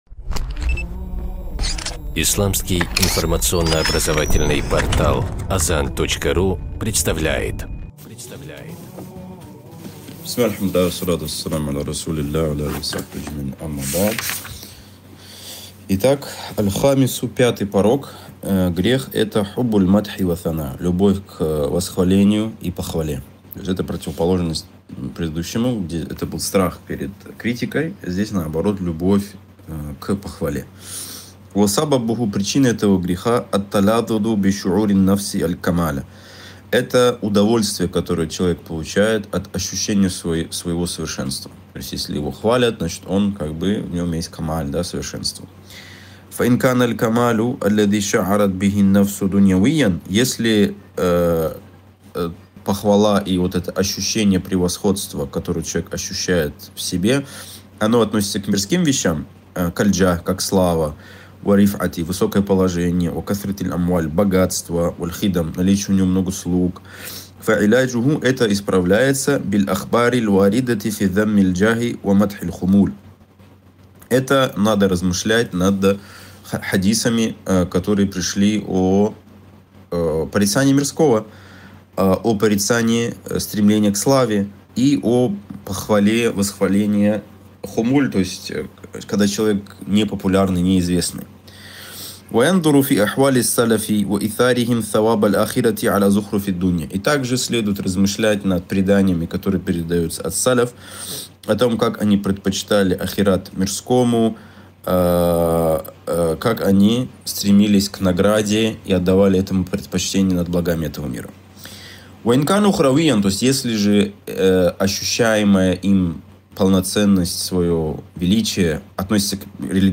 ЦИКЛЫ УРОКОВ